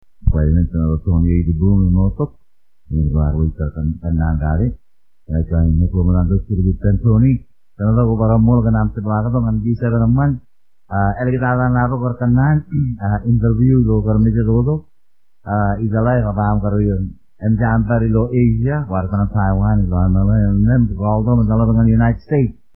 Here’s a recording of someone talking in an obscure language.